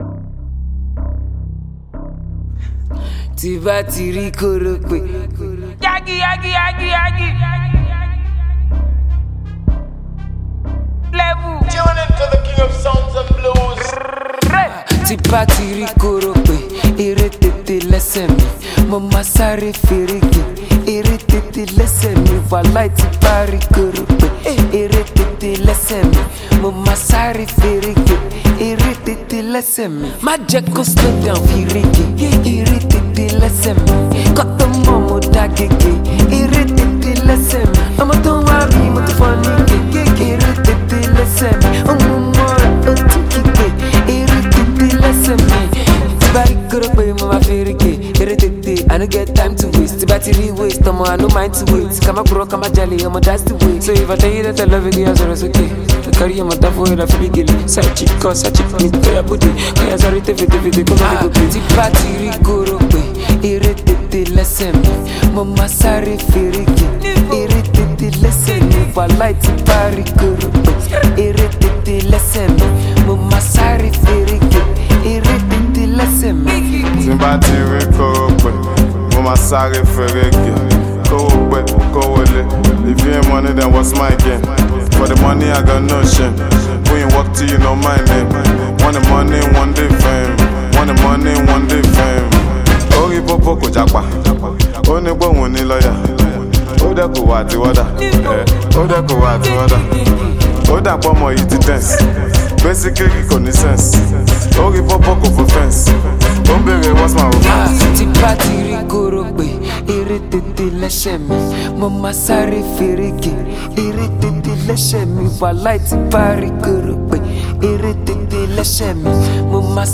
party-ready number that will keep you on the dancefloor